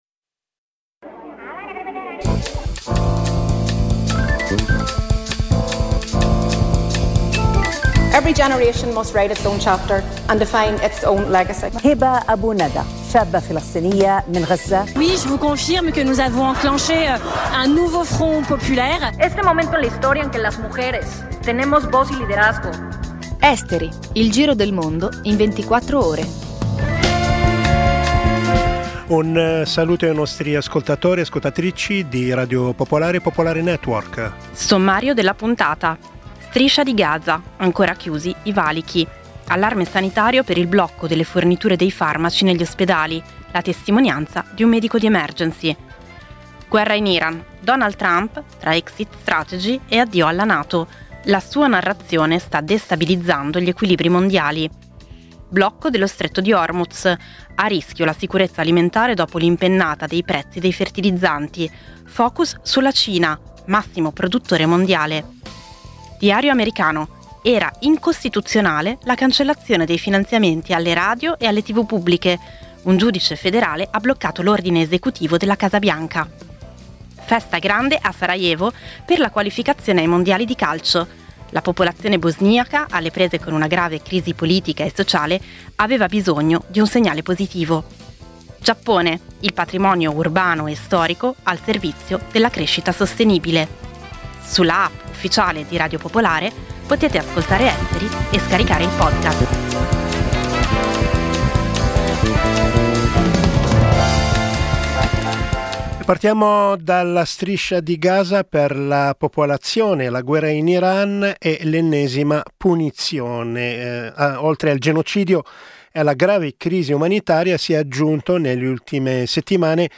Il programma combina notizie e stacchi musicali, offrendo una panoramica variegata e coinvolgente degli eventi globali.